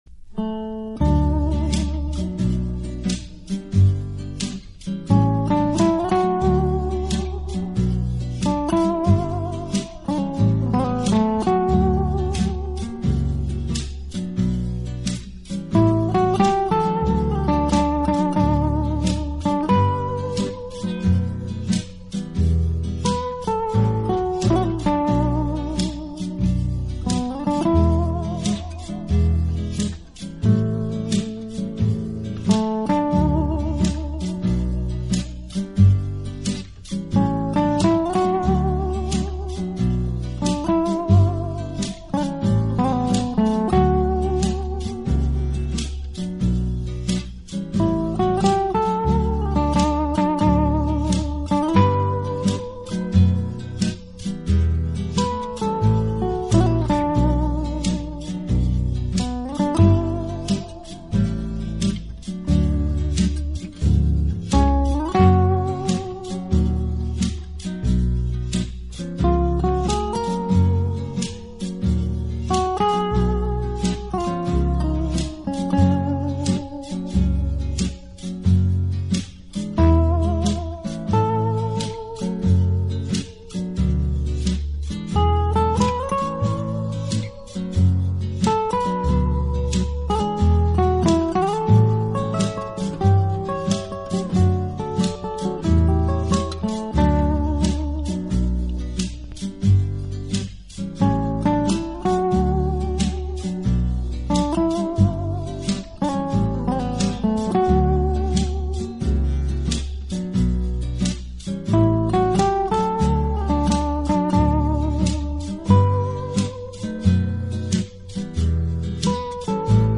【拉丁吉他】
典乐风，神气地呈现出沉静中蕴涵迷惑人心的风采。